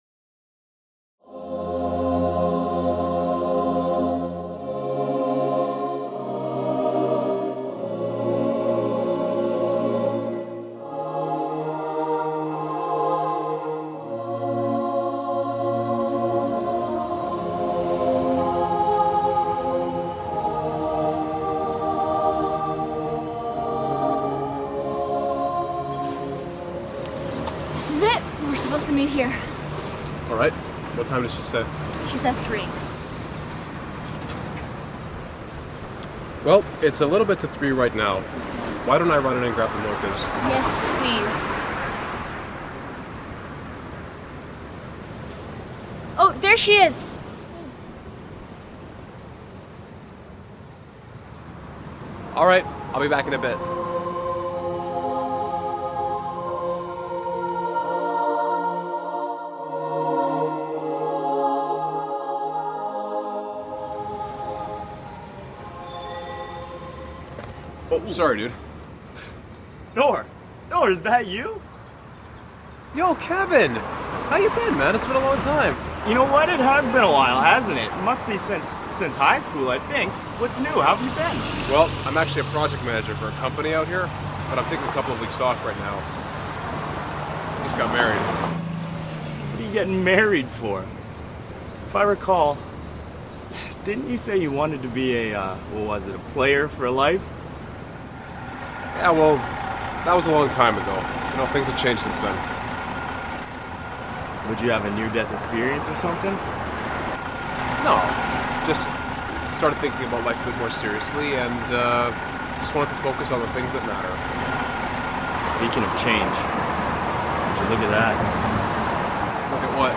This powerful short film follows the story of a Muslim couple whose marriage is tested when the husband discovers information about his wife’s past before she practiced Islam. The drama explores themes of forgiveness, repentance, and the Islamic principle that accepting Islam wipes the slate clean — raising the critical question: how should Muslims handle the past sins of those who have sincerely changed?